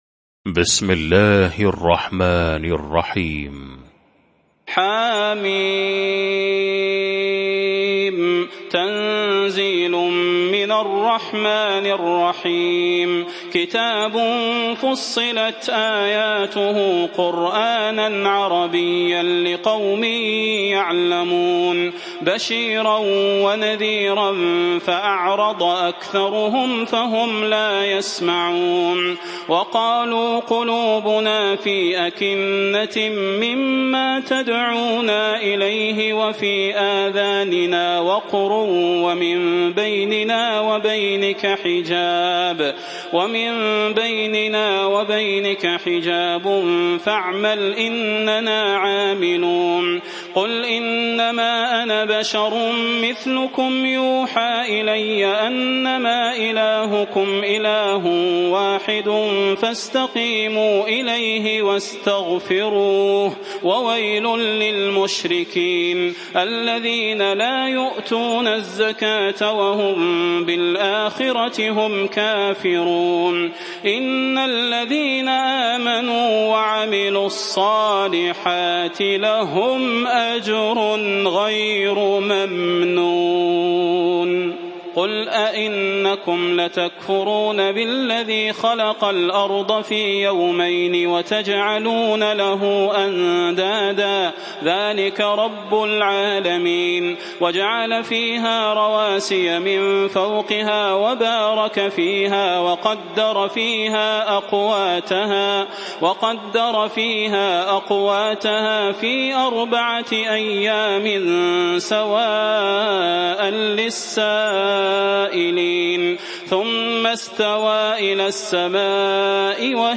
المكان: المسجد النبوي الشيخ: فضيلة الشيخ د. صلاح بن محمد البدير فضيلة الشيخ د. صلاح بن محمد البدير فصلت